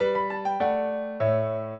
piano
minuet14-7.wav